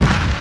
Destroy - CrunkPerc.wav